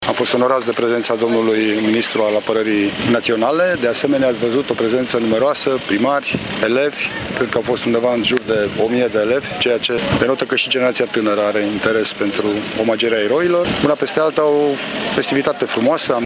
Prefectul de Mureș Lucian Goga a salutat și prezența elevilor veniți din tot județul să participe la eveniment.